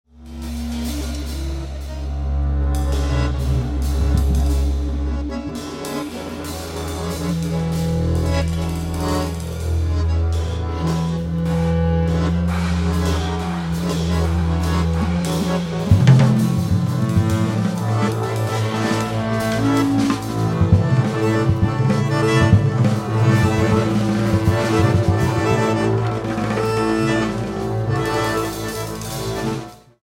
soprano sax, bassclarinet
accordion
double bass
drums